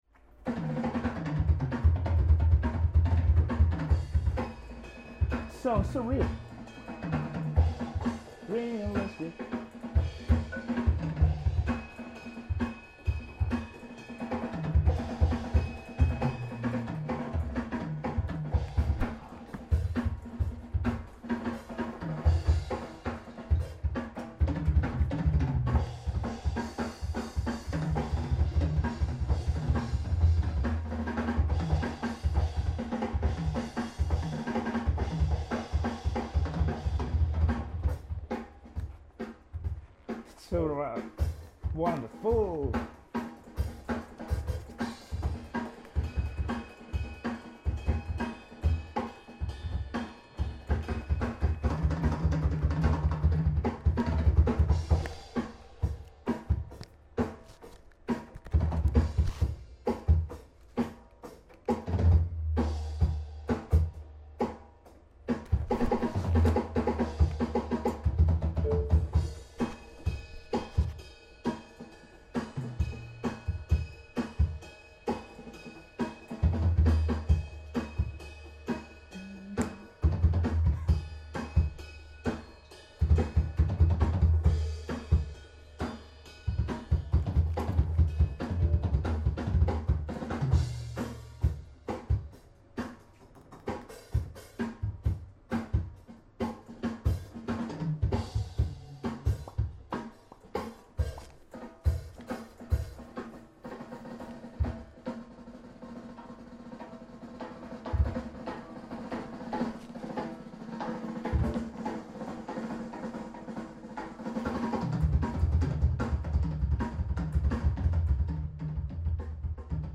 Software drums can sound real great these days.
SoundProfessionals MSbmc3 and Zoom H1